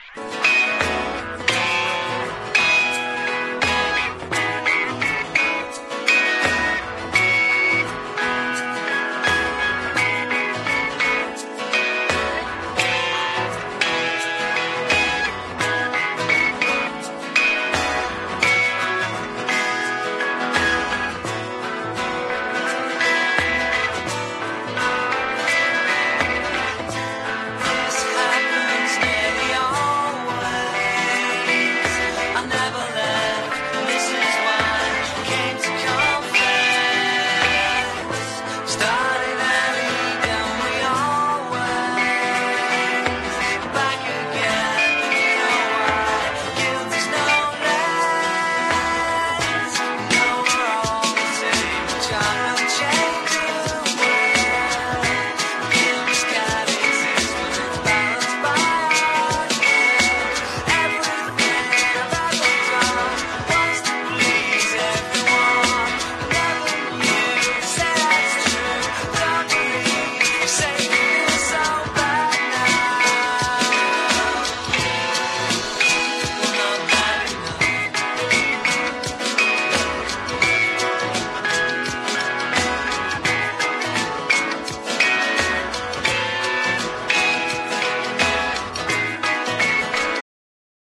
アコースティックなサウンドを中心に大らかなメロディー満載の1枚！